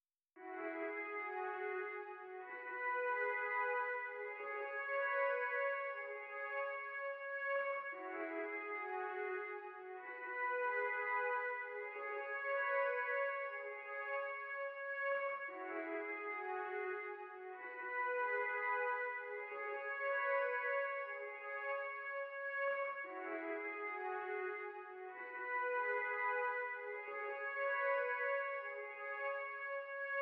violons_studio_strings_midi_long_01.wav